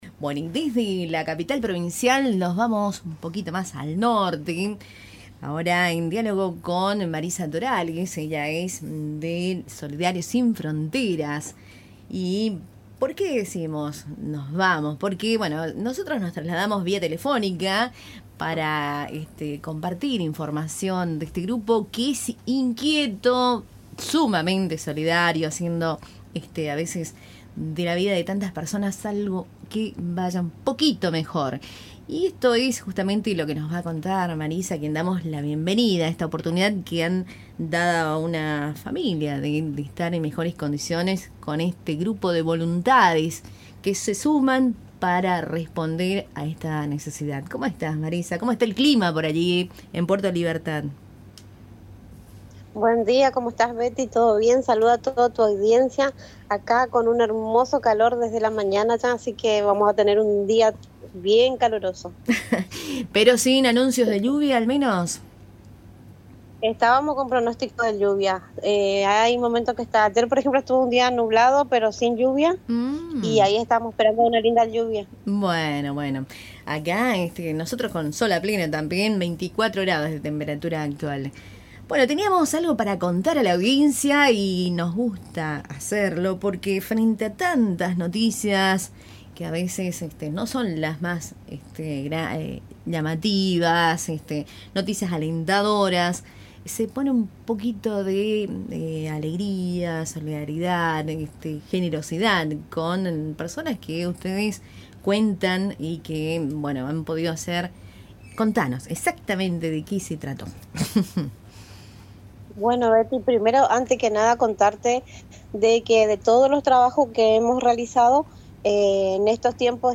Entrevista realizada en Radio Tupambaé